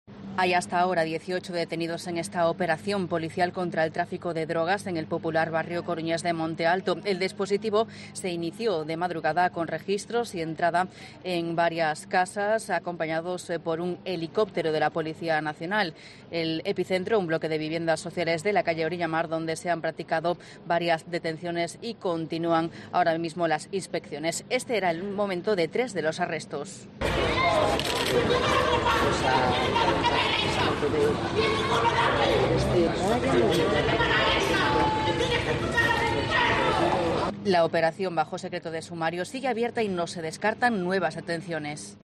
Operación contra el narcotráfico en Coruña. Crónica